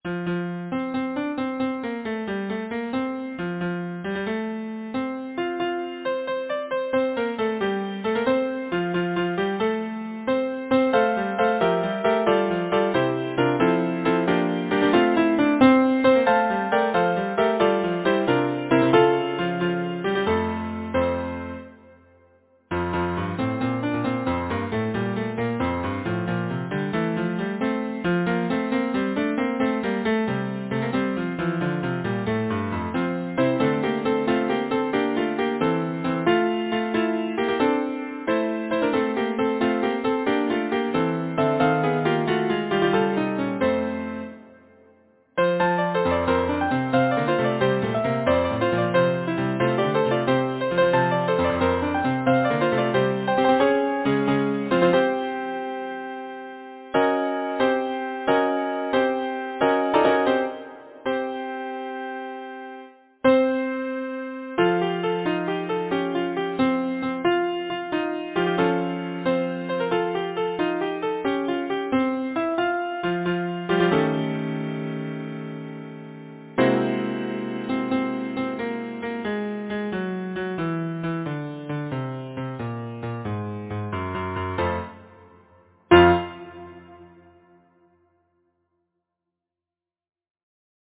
Title: The Deil’s Awa’ Composer: Anonymous (Traditional) Arranger: William Whittaker Lyricist: Robert Burns Number of voices: 4vv Voicing: SATB Genre: Secular, Partsong, Folksong
Language: Lowland Scots Instruments: A cappella